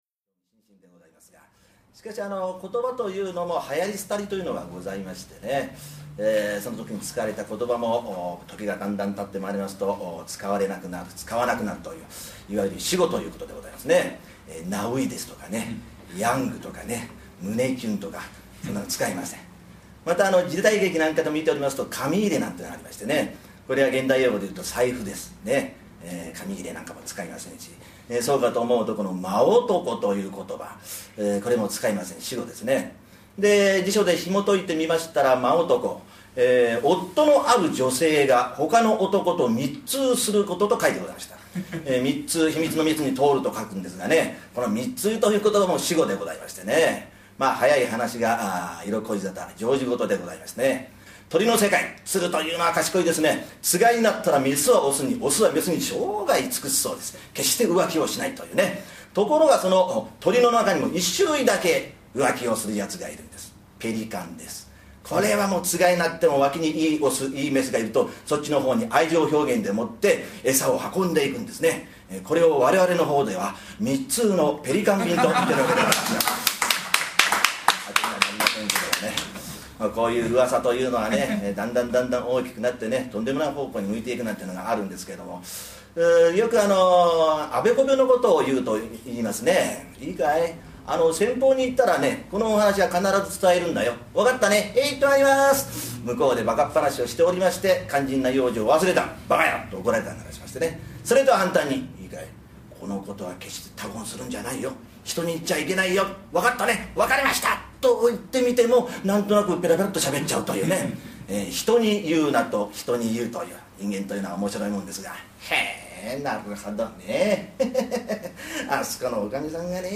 三遊亭円楽一門会が毎月1日〜15日まで両国永谷ホールで開催している両国寄席。
その両国寄席より、三遊亭円楽一門・真打の落語家による選りすぐり高座をお届けいたします！